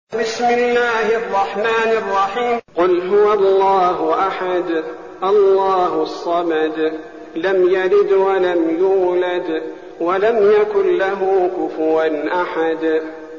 المكان: المسجد النبوي الشيخ: فضيلة الشيخ عبدالباري الثبيتي فضيلة الشيخ عبدالباري الثبيتي الإخلاص